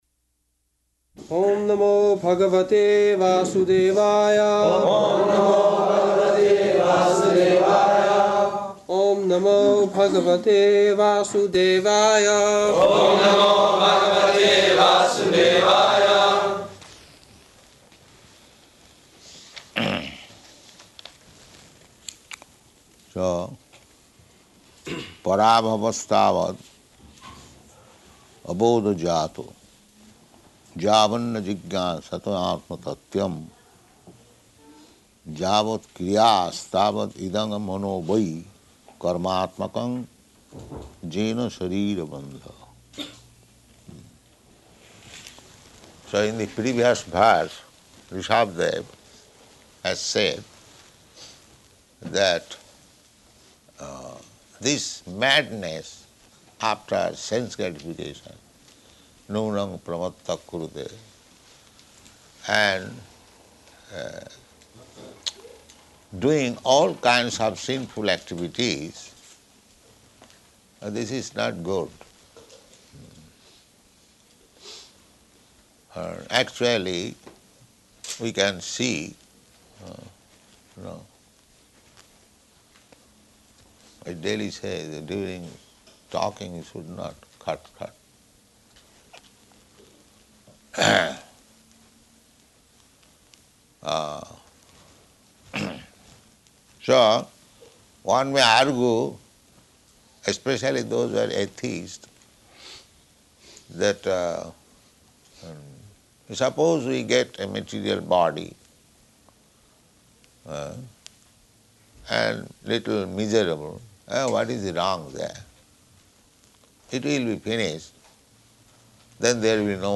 -- Type: Srimad-Bhagavatam Dated: September 10th 1973 Location: Stockholm Audio file
[Prabhupāda and devotees repeat]